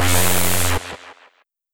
se_lazer01.wav